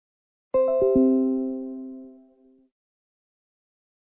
Windows 10 Notify System Sound - Botón de Efecto Sonoro